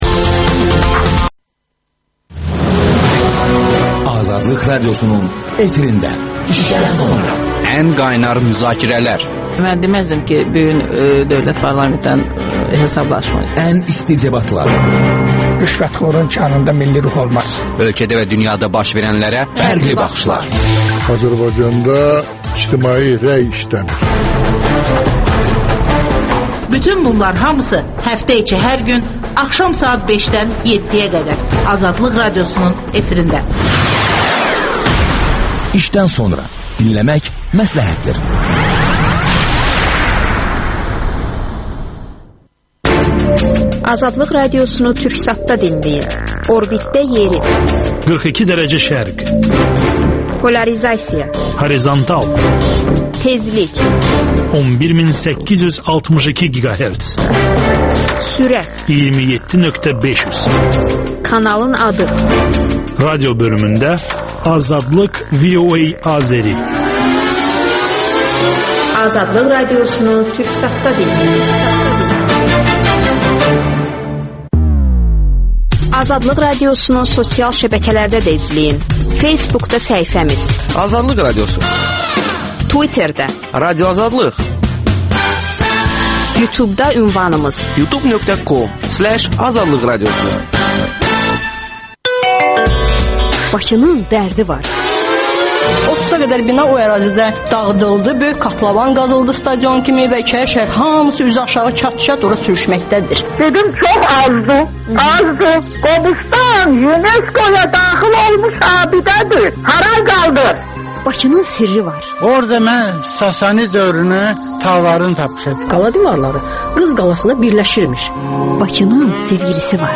Politoloqlar